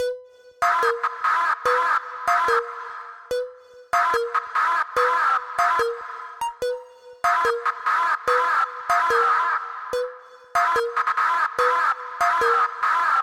城市循环2 145 BPM
Tag: 145 bpm Trap Loops Synth Loops 2.23 MB wav Key : A